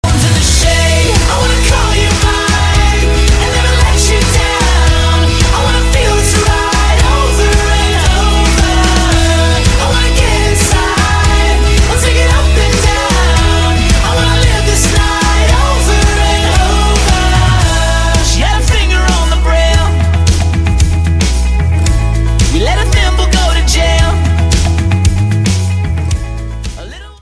• Качество: 128, Stereo
мужской вокал